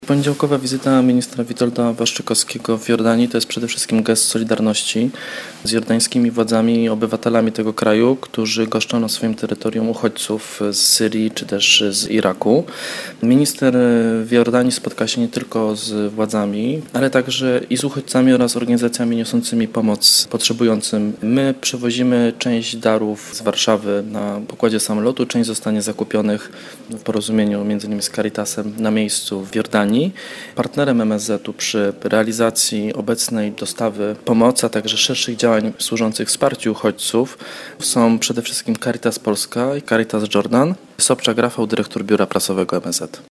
na lotnisku Okęcie, tuż przed odlotem samolotu z darami powiedział: Minister Waszczykowski w Jordanii spotka się nie tylko z władzami ale także z uchodźcami i organizacjami niosącymi pomoc potrzebującym.